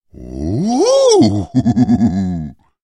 Звуки удивления, восторга
Мужчина искренне обрадовался неожиданному сюрпризу